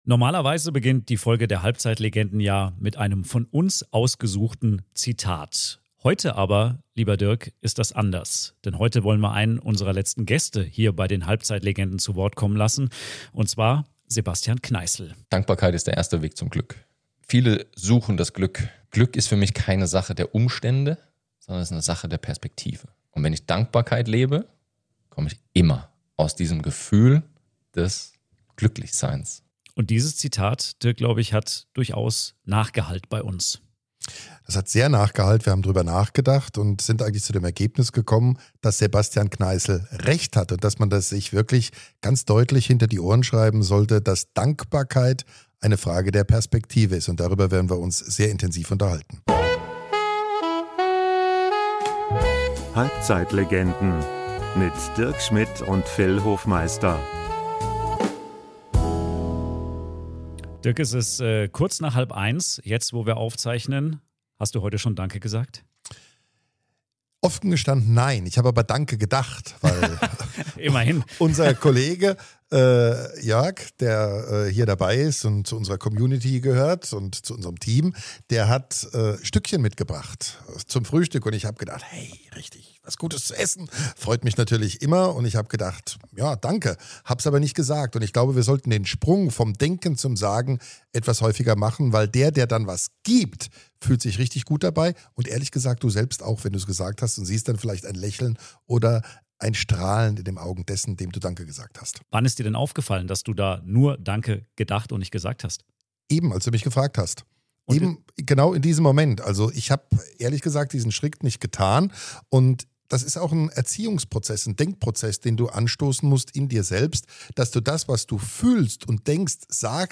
Wofür sind wir eigentlich dankbar - und zeigen wir das oft genug? Zwei Mikrofone, viele Gedanken. Eine Folge über kleine Gesten, große Einsichten und die Kunst, im Alltag das Gute nicht zu übersehen. Locker, offen und mit einer guten Portion Lebensfreude - genau so, wie’s sich gehört.